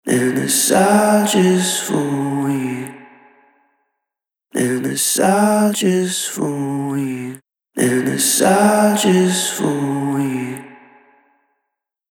Tube Amp-style Spring Reverb
Spring | Vocals | Preset: RW Tank
Spring-Eventide-Vocals-Rw-Tank.mp3